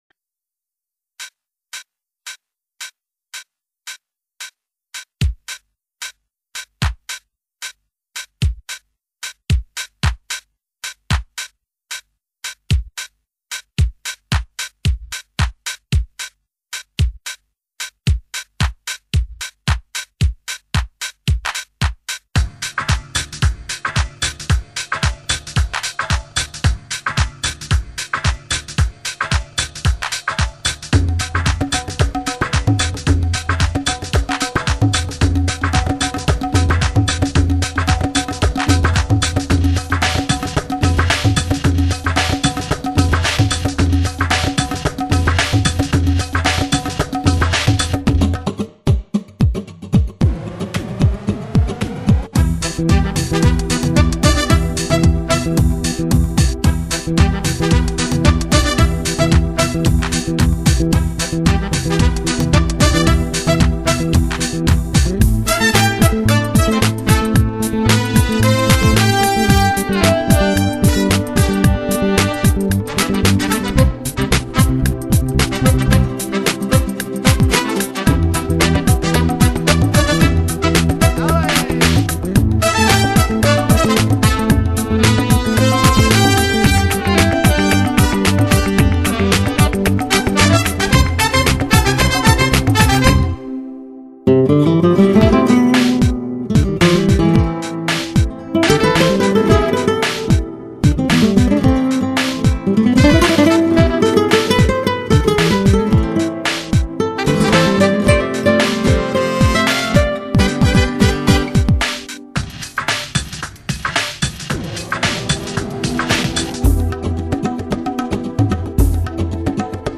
热情似火的吉他发烧碟
HIFI发烧品质，音响绝对一流。
这是一张典型的“佛朗明哥”风格的吉他音乐，具